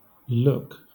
wymowa:
IPA/lʊk/, SAMPA/lUk/